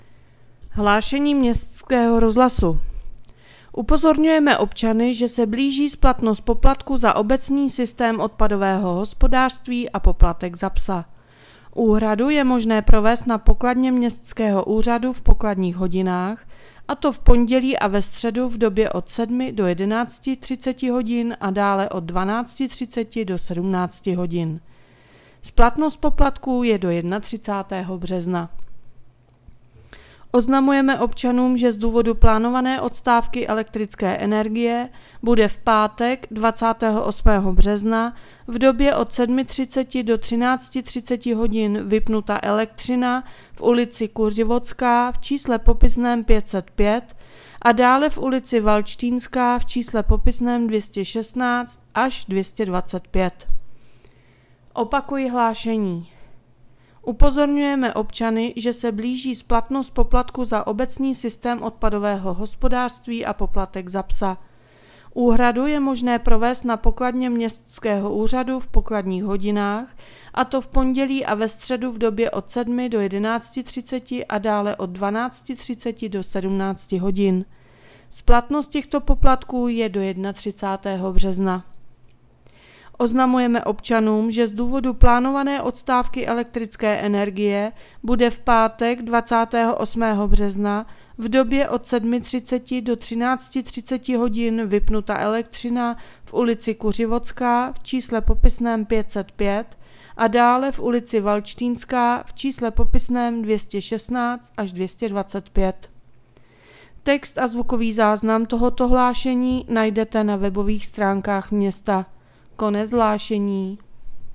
Hlaášení městského rozhlasu 26.3.2025